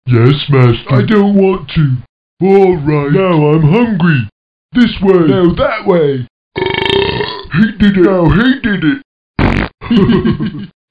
war2-ogre.mp3